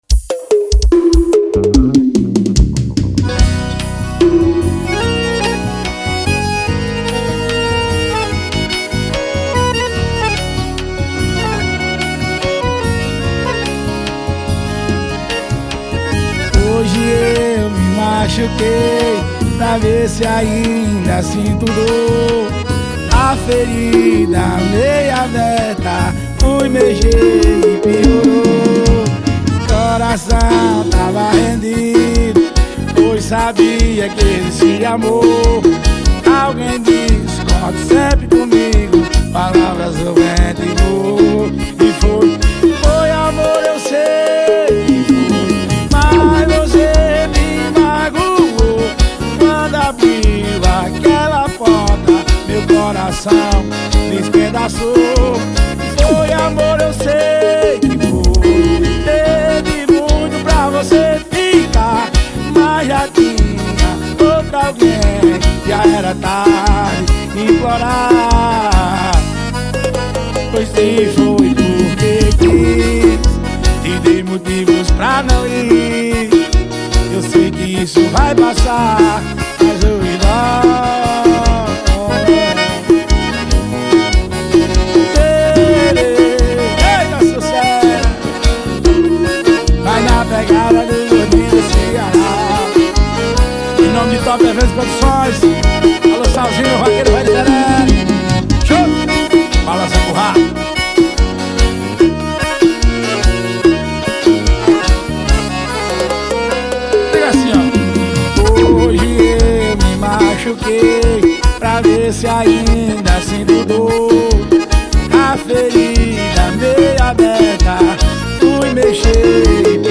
2024-07-14 00:22:20 Gênero: Sertanejo Views